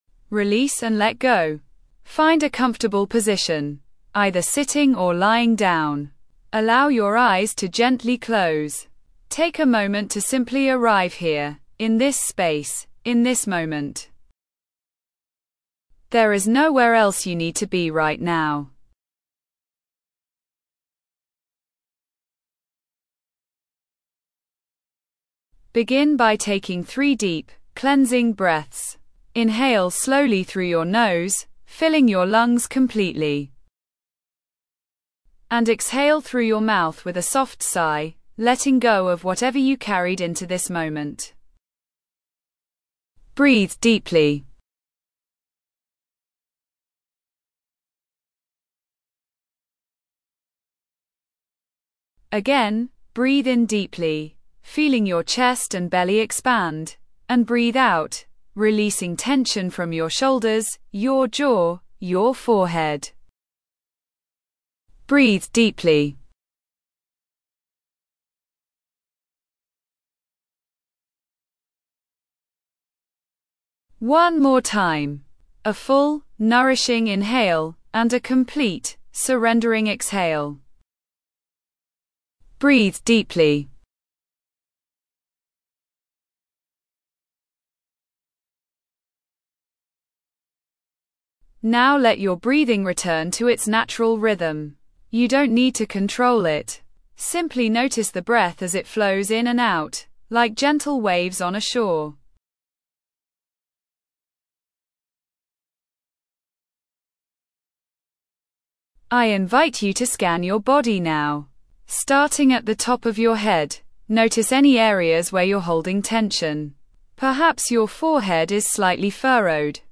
Release and Let Go: A Guided Meditation for Stress Relief
A gentle beginner meditation to help you release tension and find calm during stressful moments.